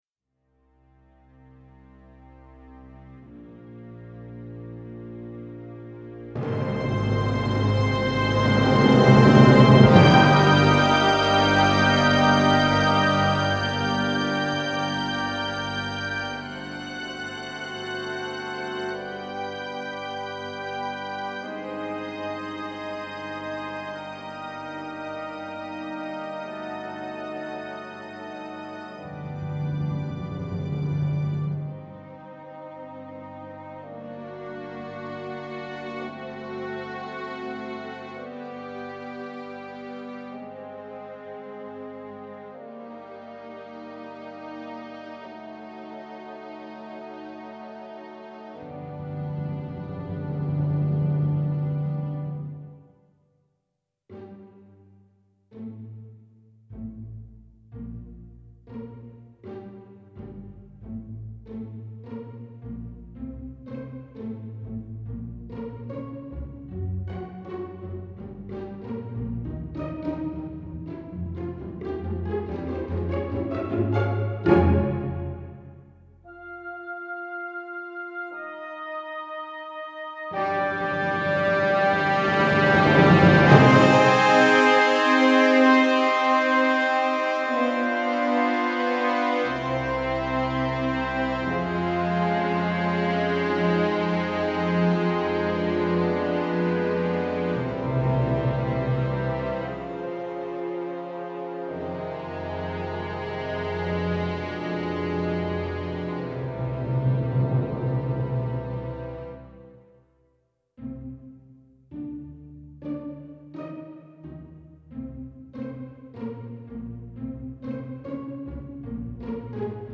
ブラームス：交響曲 第1番 ハ短調 Op.68 第3,第4楽章 [2023] / Johannes Brahms：Symphony No.1 in C minor, Op.68 3.
Adagio - Allegro non troppo ma con brio